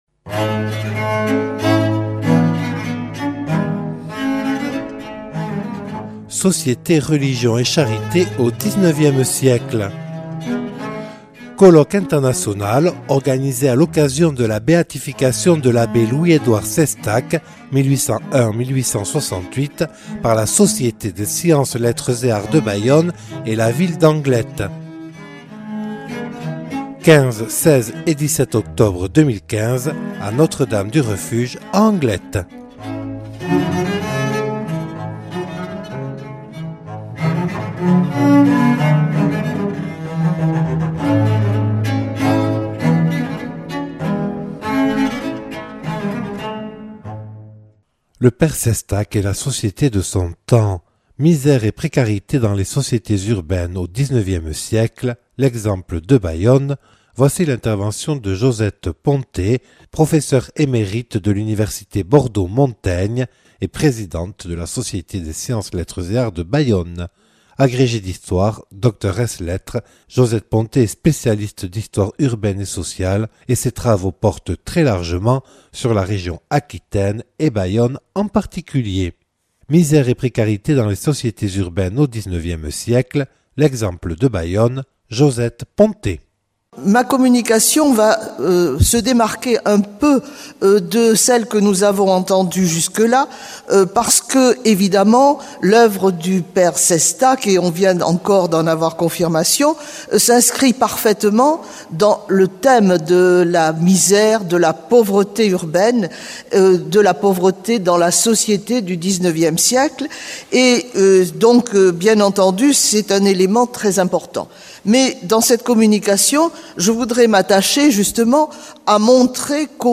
(Enregistré le 15/10/2015 à Notre Dame du Refuge à Anglet).